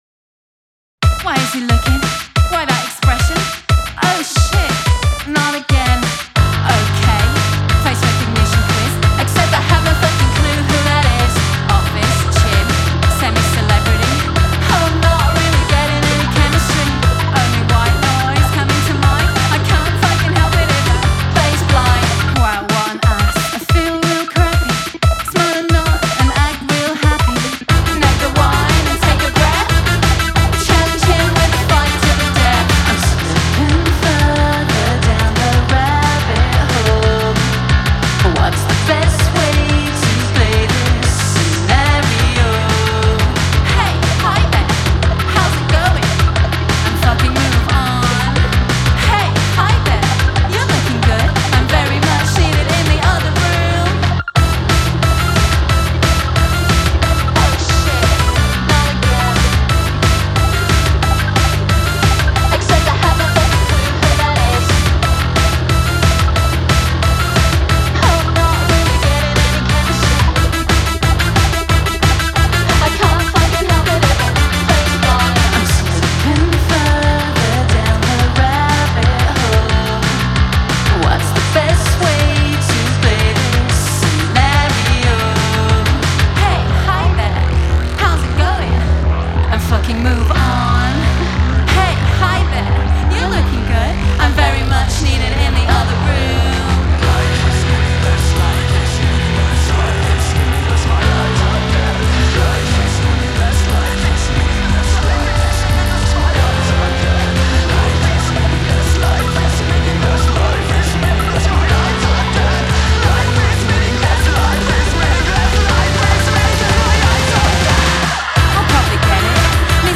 genre: Electroclash